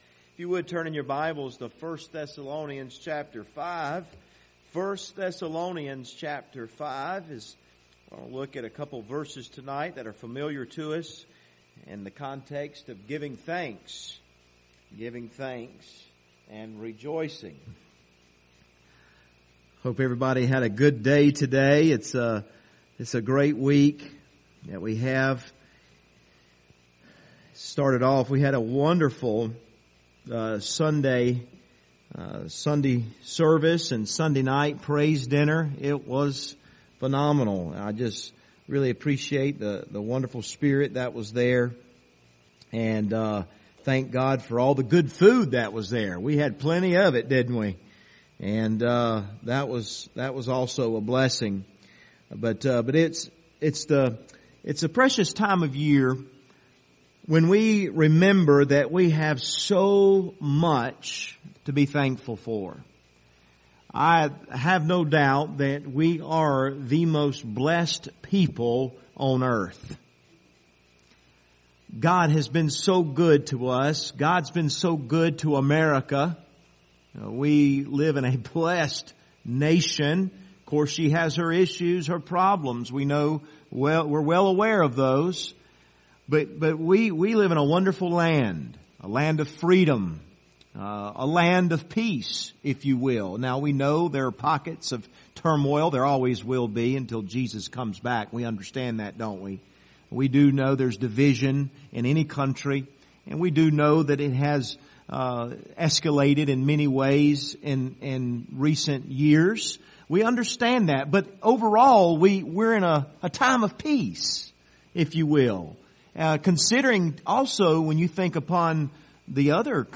Passage: 1 Thessalonians 5:16-18 Service Type: Wednesday Evening View this video on Facebook Topics